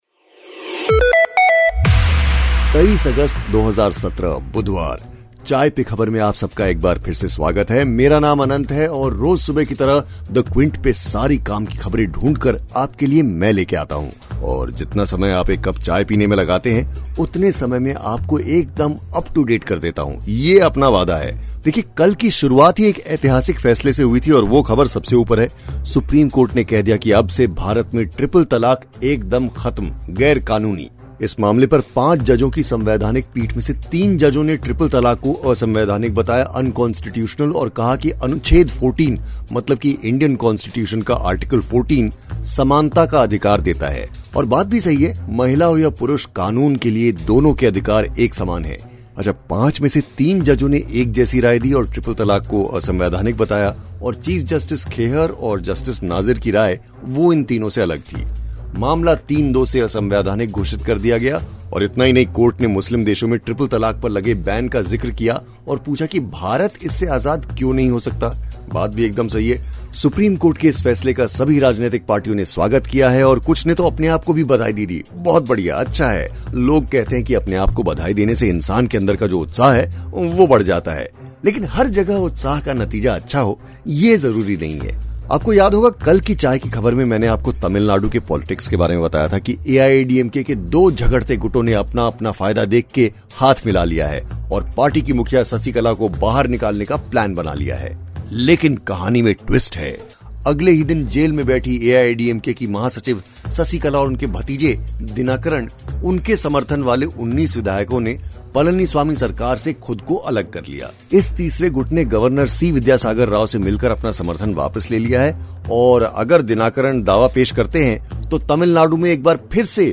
सुनिए बुधवार की बड़ी खबरें क्विंट हिंदी के स्टाइल में फटाफट.